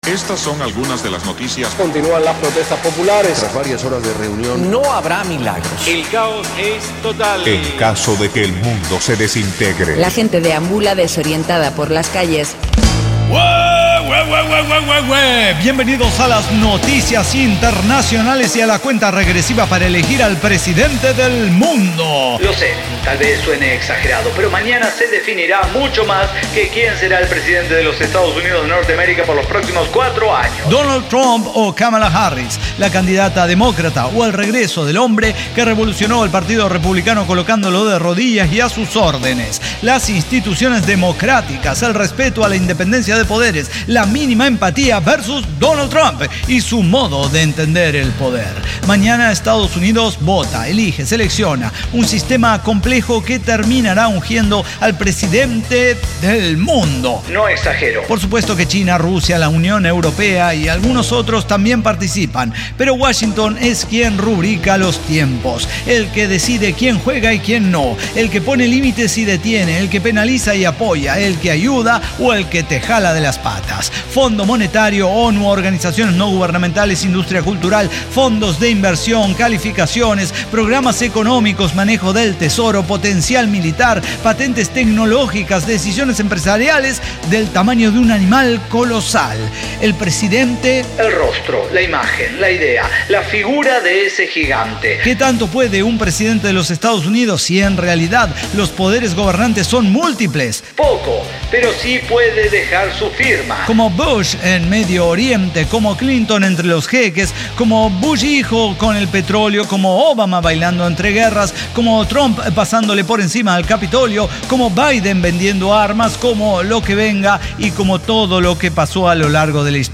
ECDQEMSD podcast El Cyber Talk Show – episodio 5906 El Presidente del Mundo – Entre Kamala Harris o Donald Trump